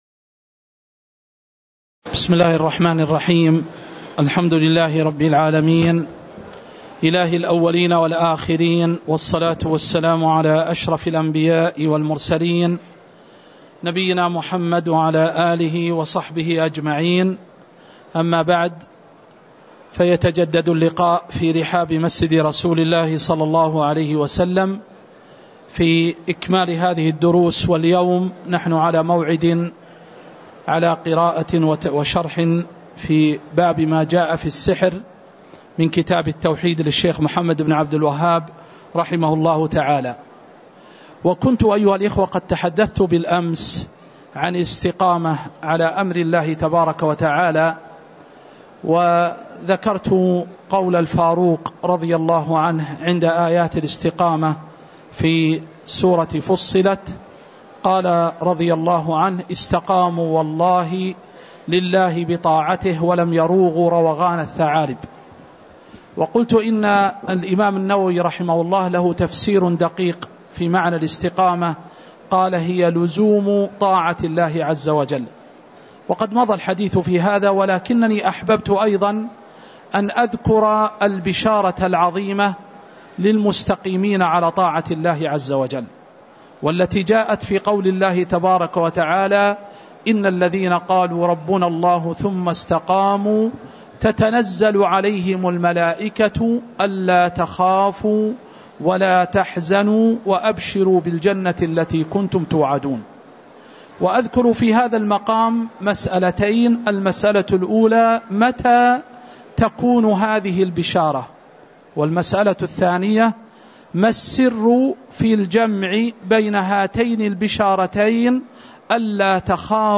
تاريخ النشر ١٣ شوال ١٤٤٠ هـ المكان: المسجد النبوي الشيخ